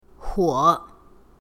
huo3.mp3